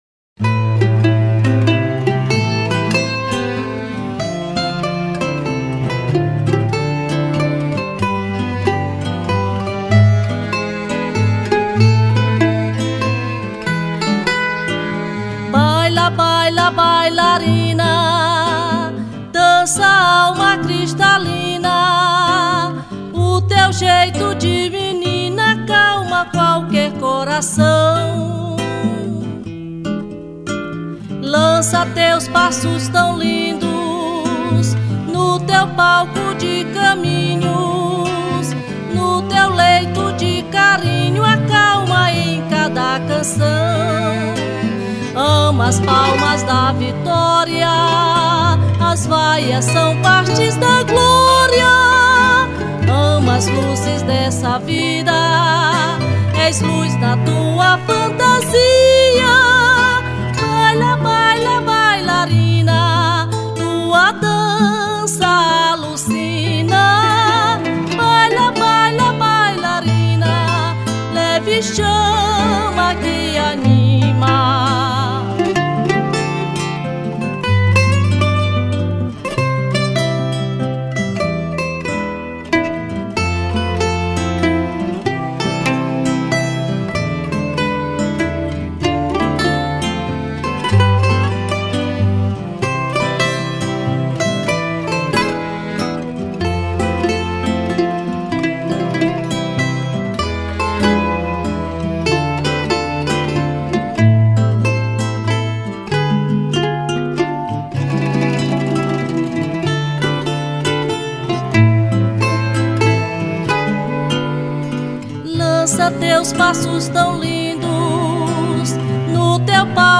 02:49:00   Valsa